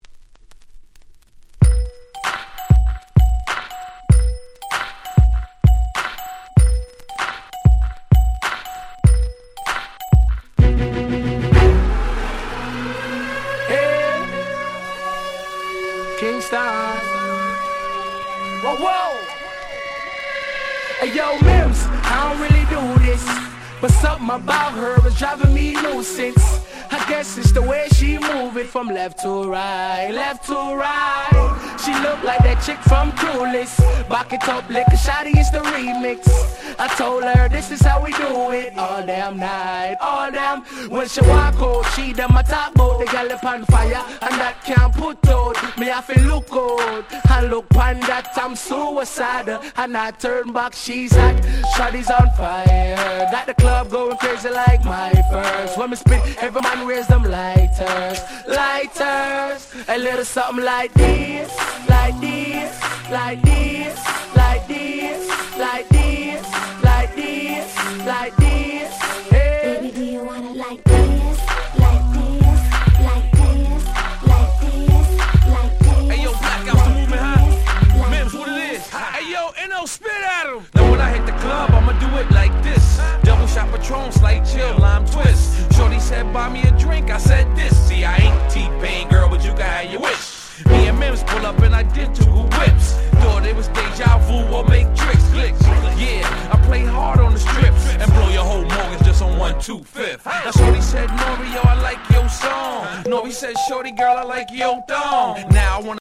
07' Smash Hit Hip Hop !!